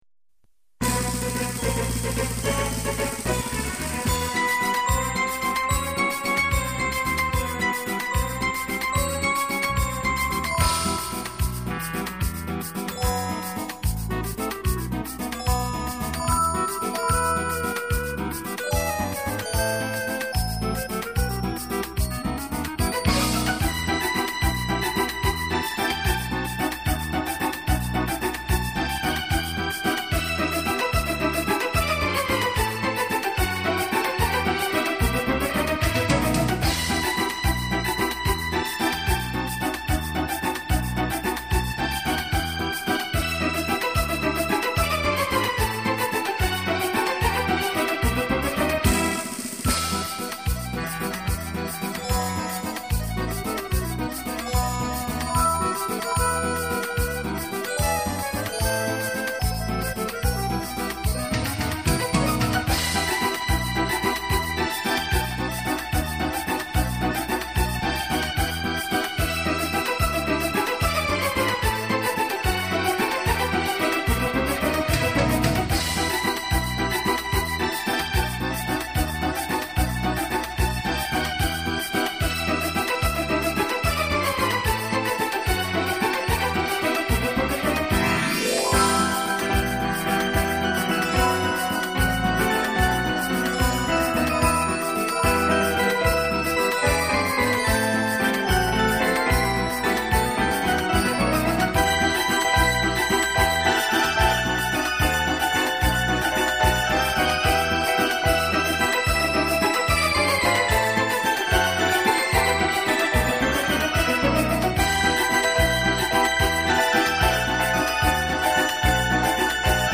минусовка версия 16579